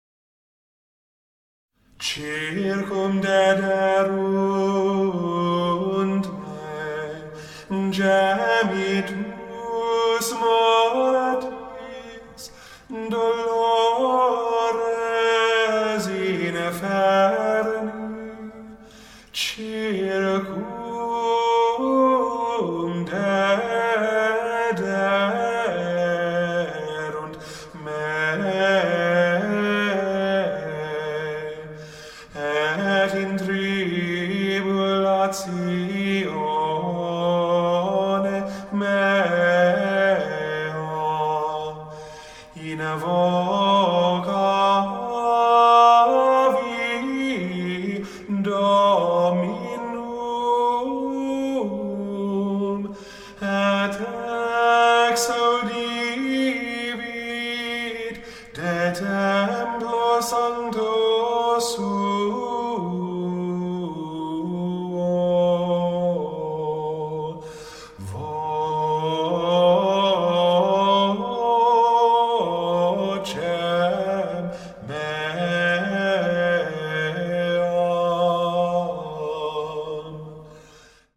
circumdederunt-me-introit-berr.mp3